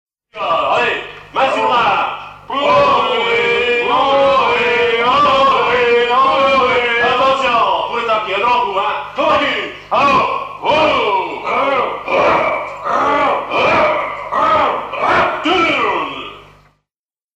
gestuel : à hisser à grands coups
circonstance : maritimes
Genre brève
Pièce musicale éditée